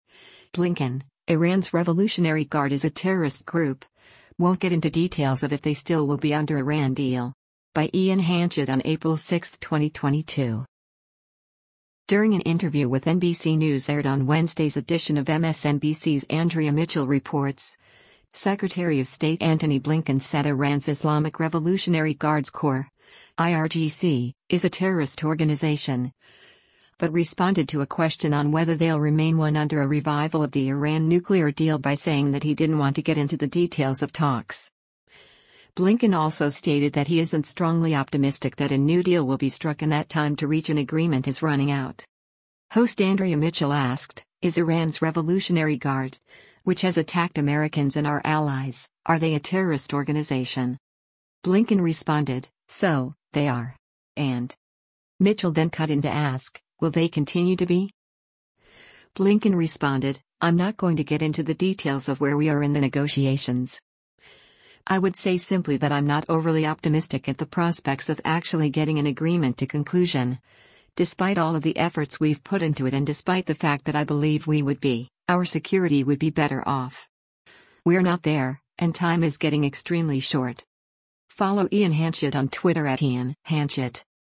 During an interview with NBC News aired on Wednesday’s edition of MSNBC’s “Andrea Mitchell Reports,” Secretary of State Antony Blinken said Iran’s Islamic Revolutionary Guards Corps (IRGC) is a terrorist organization, but responded to a question on whether they’ll remain one under a revival of the Iran nuclear deal by saying that he didn’t want to get into the details of talks. Blinken also stated that he isn’t strongly optimistic that a new deal will be struck and that time to reach an agreement is running out.